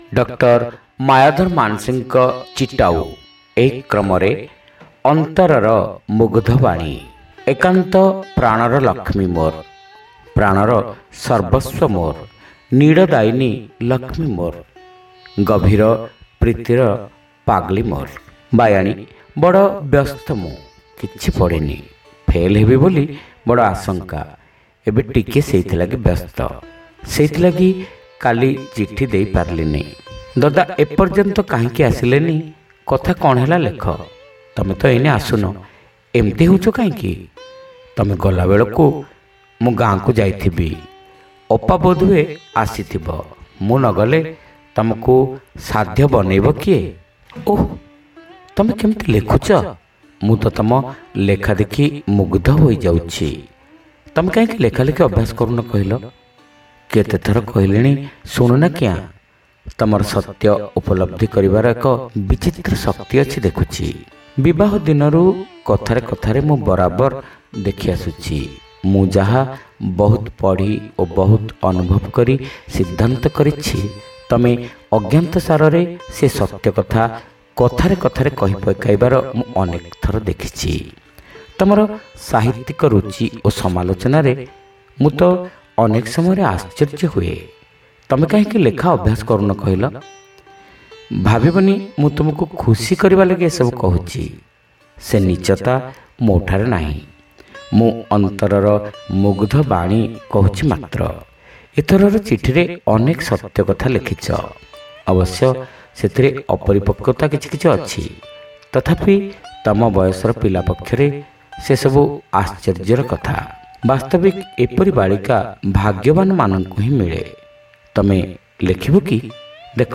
ଶ୍ରାବ୍ୟ ଗଳ୍ପ : ଅନ୍ତରର ମୁଗ୍ଧବାଣୀ